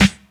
• 90's Thick Hip-Hop Snare Sound G Key 34.wav
Royality free snare drum tuned to the G note. Loudest frequency: 1484Hz
90s-thick-hip-hop-snare-sound-g-key-34-Ind.wav